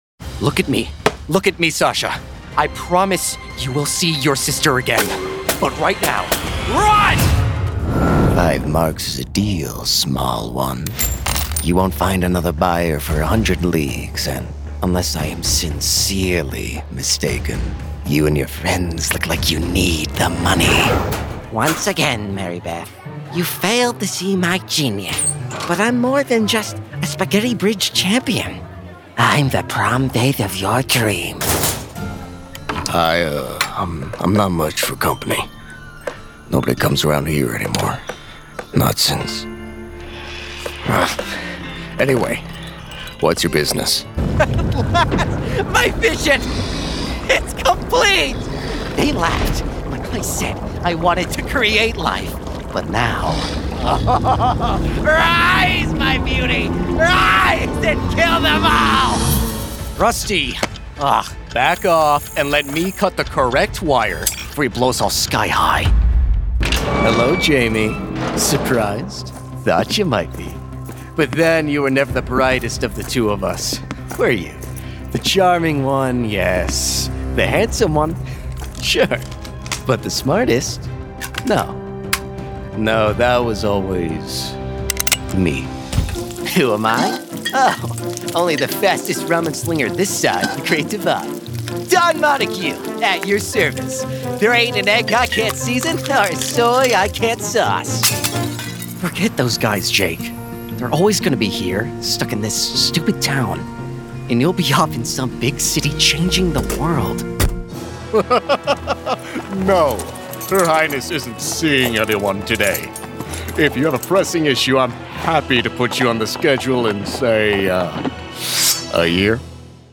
Teenager, Young Adult, Adult, Mature Adult
Has Own Studio
british rp | character
southern us | natural
standard us | natural
ANIMATION 🎬
character lisp